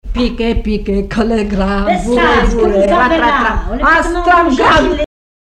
formulette enfantine : jeu des doigts
Chansons traditionnelles et populaires
Pièce musicale inédite